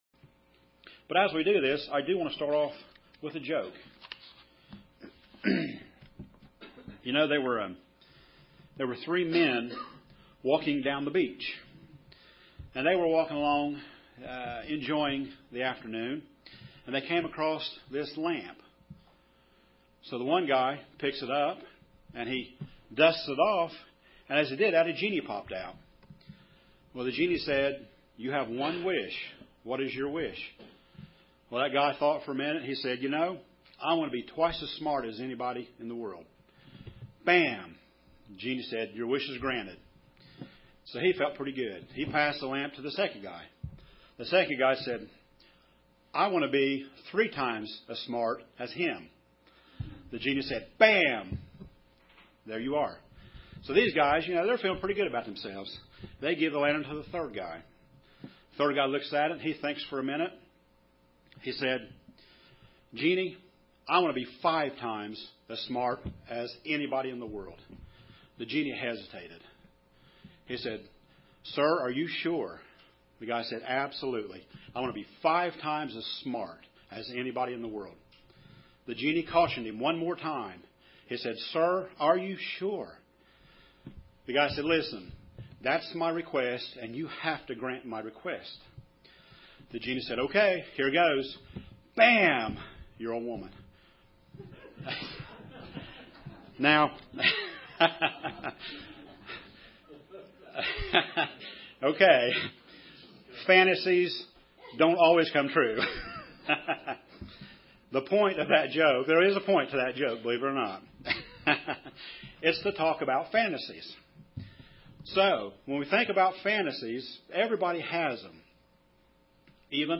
Given in Paintsville, KY
UCG Sermon Studying the bible?